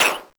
yellowPop.ogg